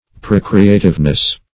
procreativeness - definition of procreativeness - synonyms, pronunciation, spelling from Free Dictionary
Procreativeness \Pro"cre*a`tive*ness\, n.